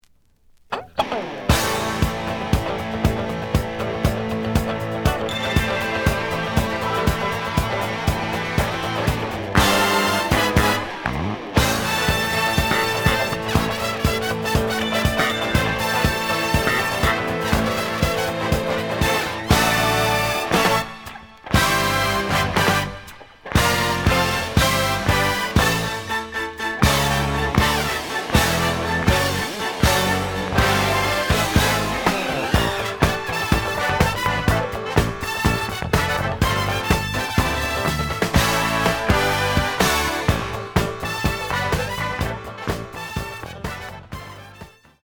The audio sample is recorded from the actual item.
●Genre: Disco
B side plays good.)